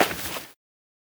PixelPerfectionCE/assets/minecraft/sounds/item/hoe/till1.ogg at 0cc5b581cc6f975ae1bce078afd85fe00e0d032f
till1.ogg